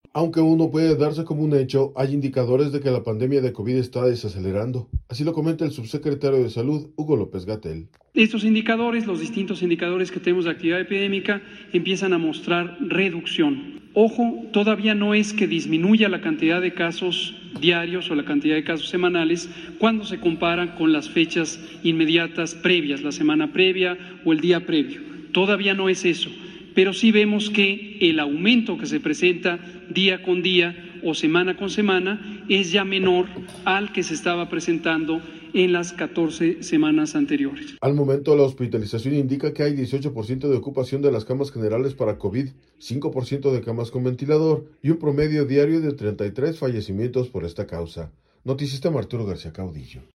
Aunque aún no puede darse como un hecho, hay indicadores de que la pandemia de Covid está desacelerando, así lo comenta el subsecretario de Salud, Hugo López-Gatell.